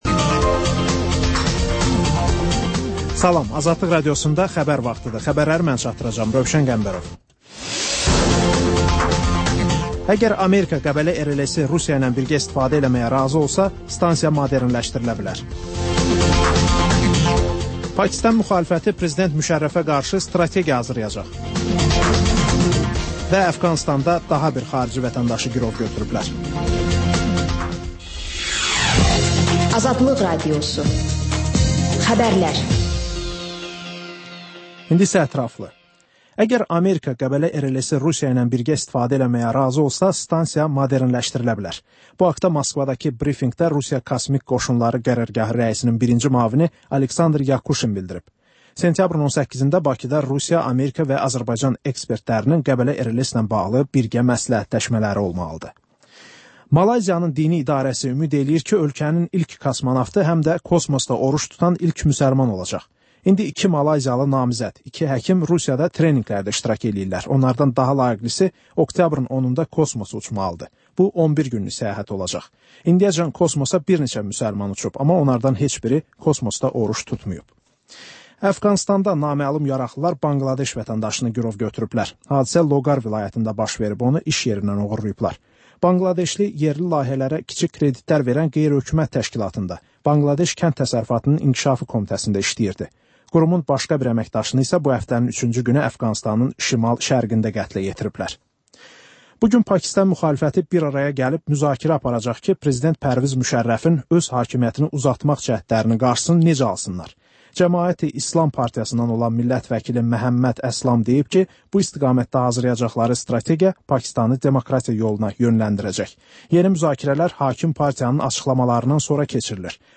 Xəbərlər, sonra CAN BAKI: Bakının ictimai və mədəni yaşamı, düşüncə və əyləncə həyatı…